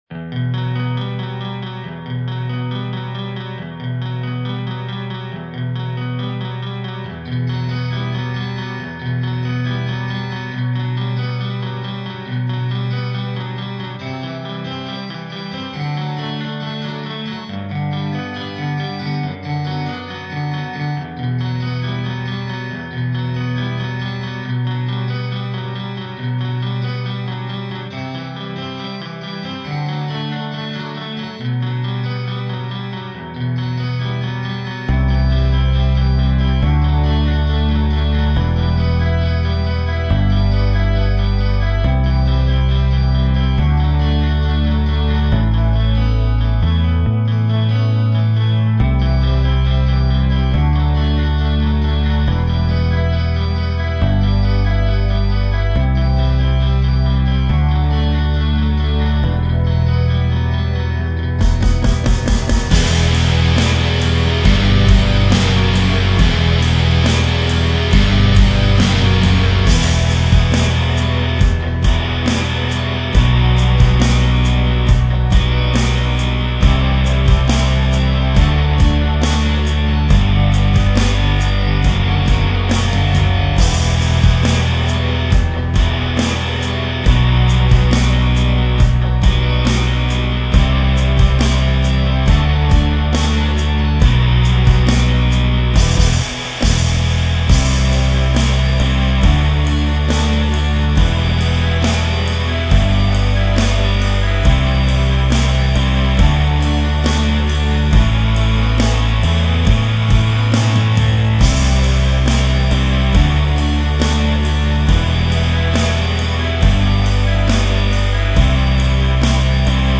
Инструментальная темка